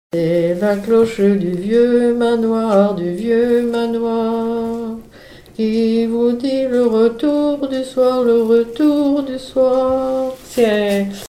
Témoignages et chansons
Pièce musicale inédite